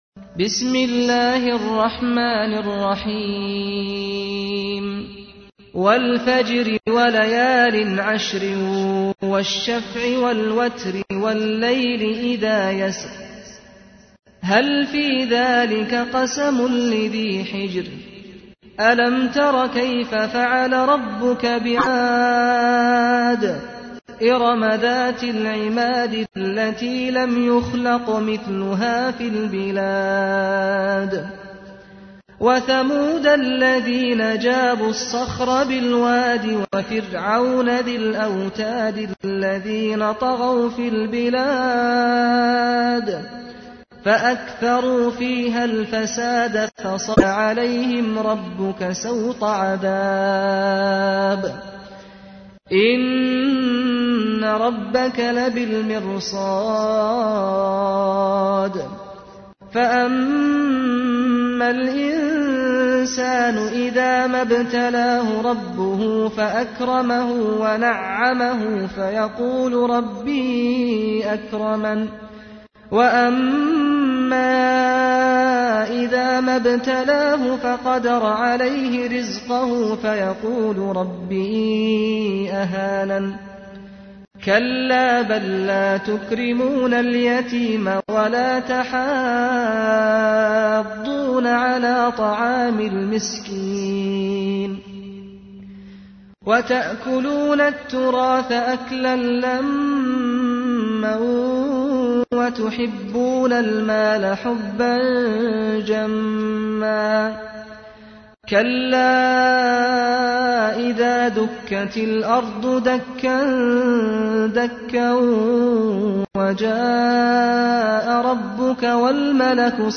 تحميل : 89. سورة الفجر / القارئ سعد الغامدي / القرآن الكريم / موقع يا حسين